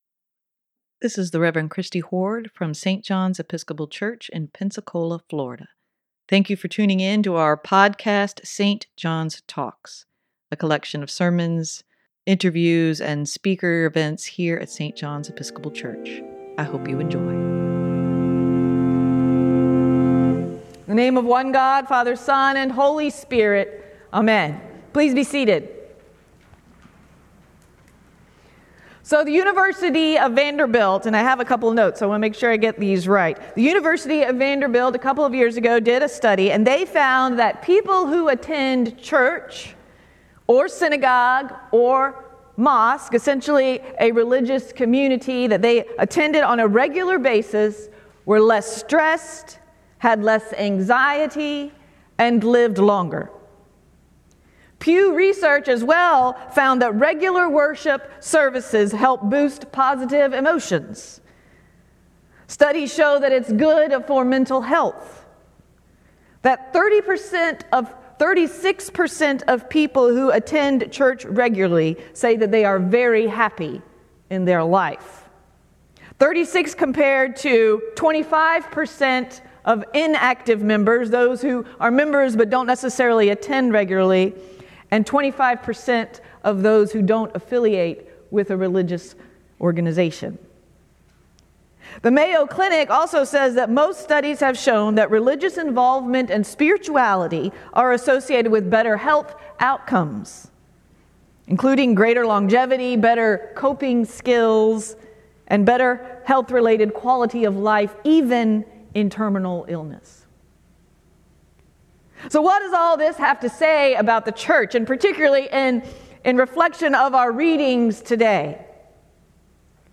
Sermon
sermon-9-10-23.mp3